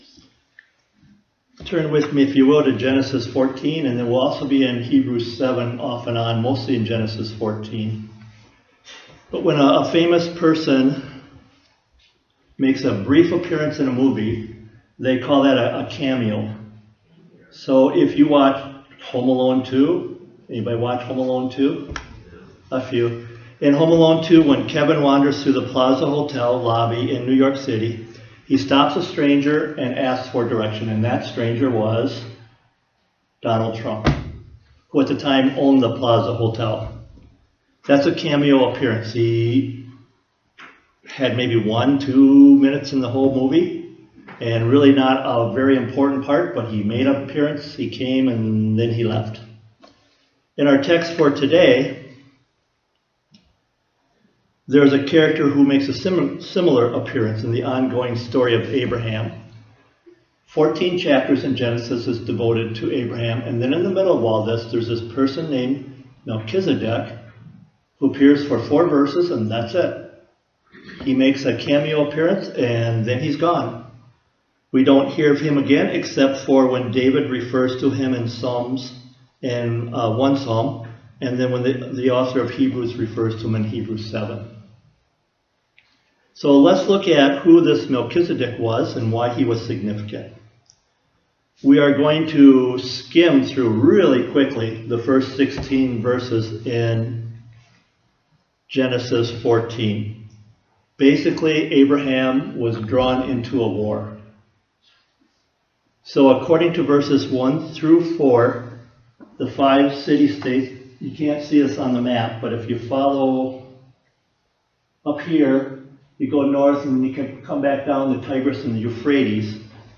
Sermons | Westview Primitive Methodist Church